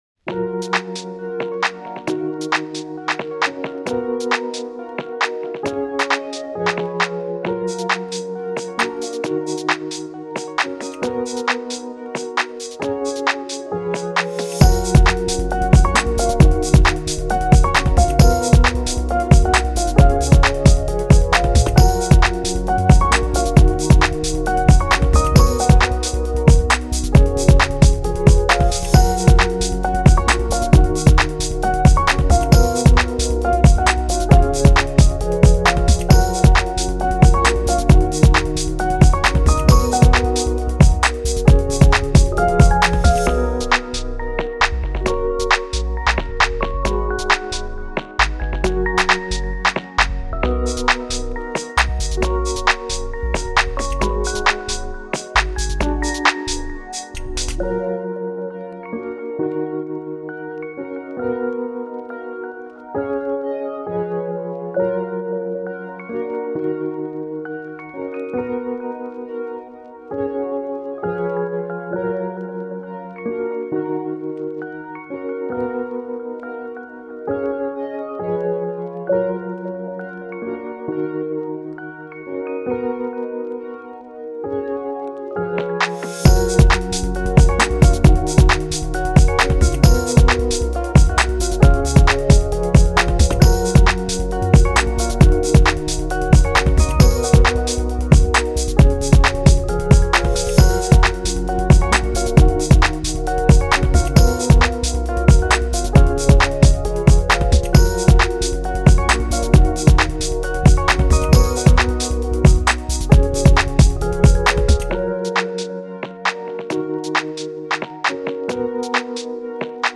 チル・穏やか